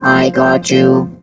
rick_kill_vo_03.wav